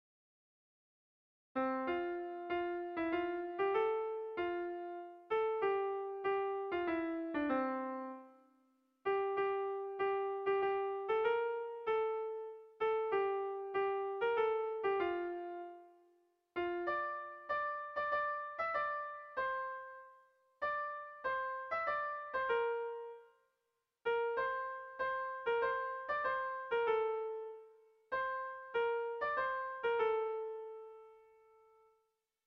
Oi zer bizimodua - Bertso melodies - BDB.
Kontakizunezkoa
Zortziko txikia (hg) / Lau puntuko txikia (ip)